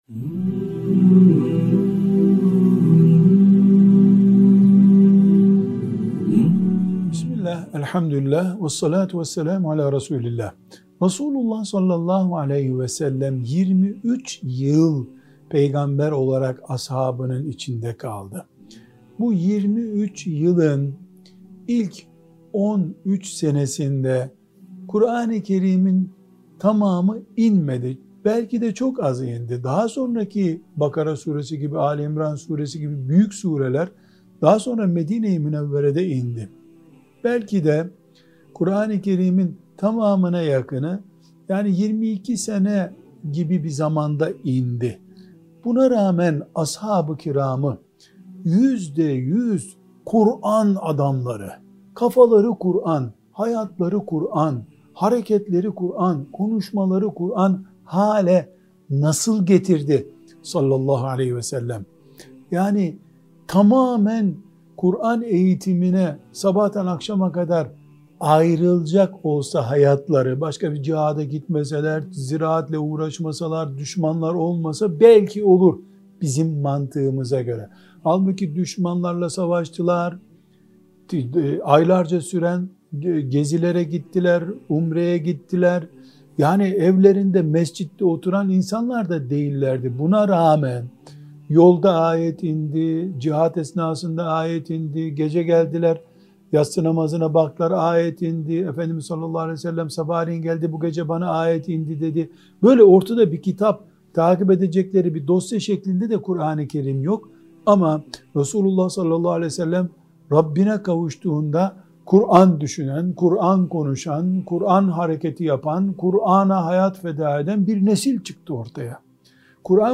1. Sohbet Arşivi